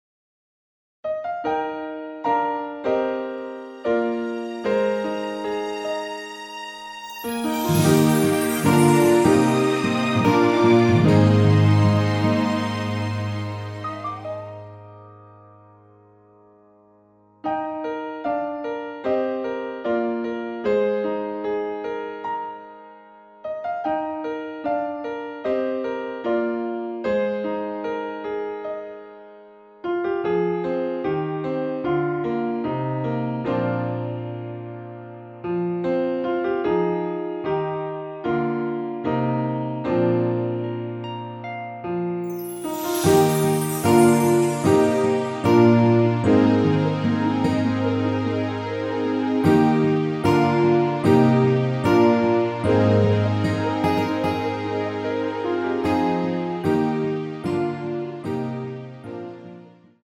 원키에서 (+1)올린 MR 입니다.(미리듣기 참조)
Eb
앞부분30초, 뒷부분30초씩 편집해서 올려 드리고 있습니다.
중간에 음이 끈어지고 다시 나오는 이유는